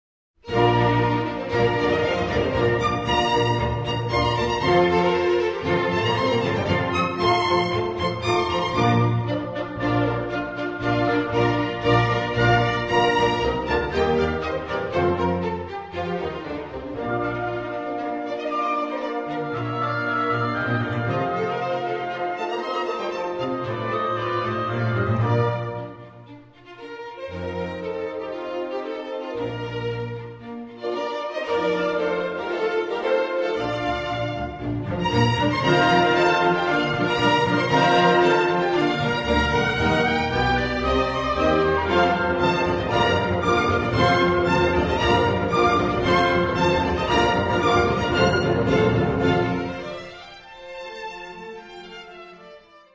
Concerto in B Flat Major (1. Allegro Maestoso
CD Concertos for violin